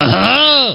Line of Krunch in Diddy Kong Racing.
Krunch_(overtaking)_4.oga.mp3